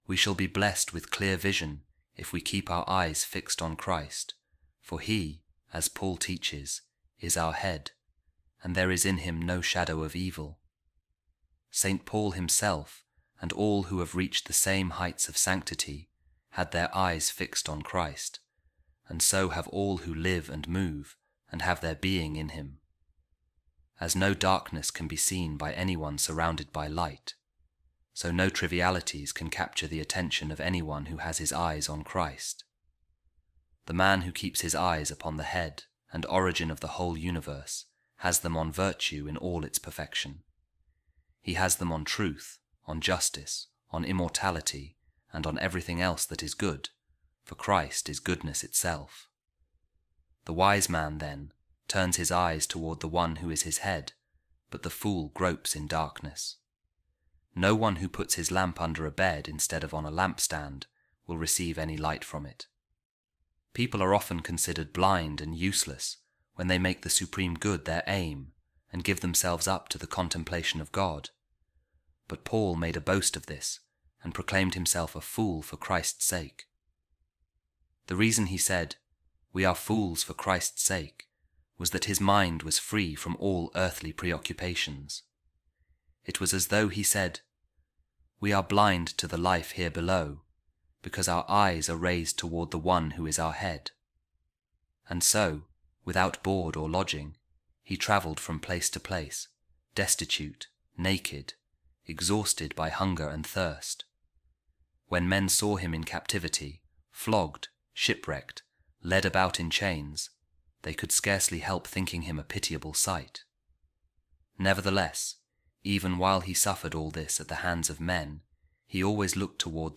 A Reading From The Homilies On Ecclesiastes Of Saint Gregory Of Nyssa | The Wise Man’s Eyes Are Fixed On His Head